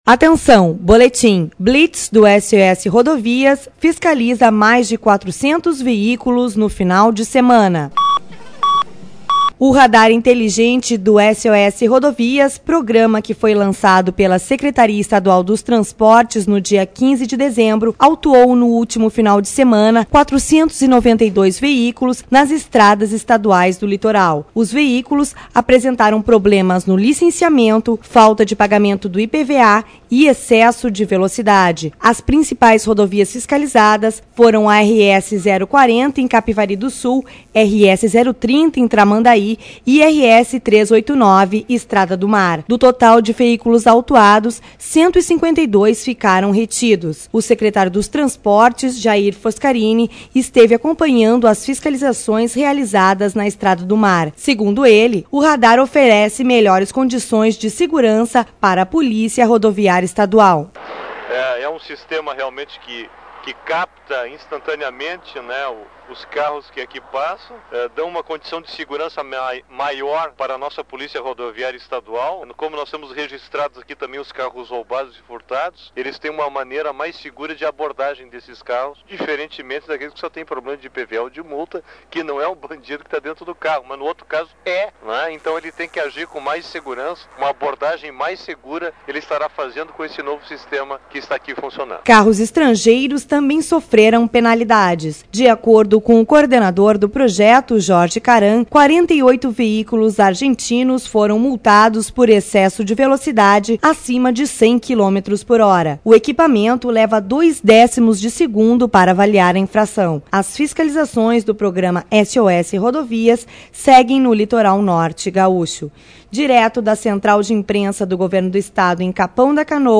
O radar inteligente do SOS Rodovias, programa que foi lançado pela Secretaria Estadual dos Transportes no dia 15 de dezembro, autuou no último final de semana 492 veículos nas estradas estaduais do litoral. (sonora: secretário dos transportes, Jair Fos